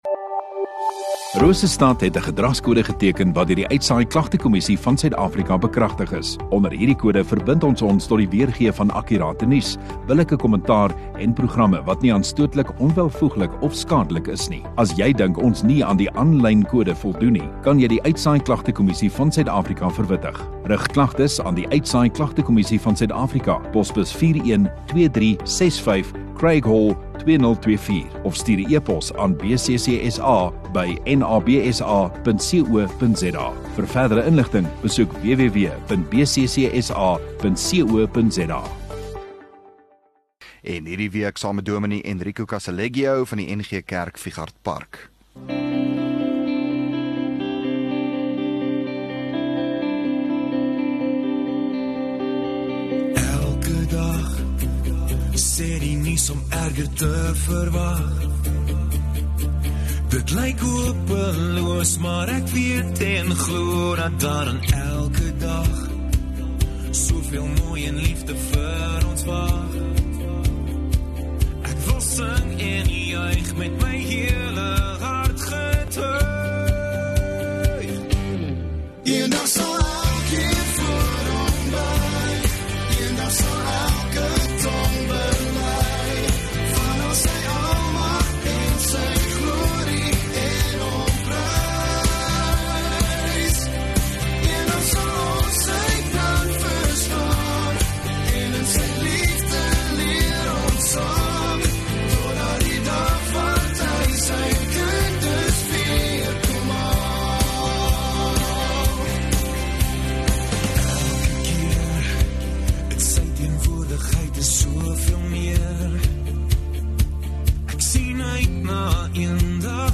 7 Jul Maandag Oggenddiens